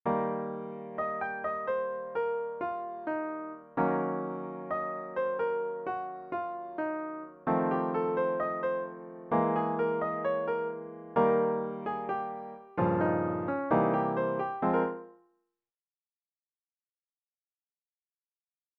アドリブ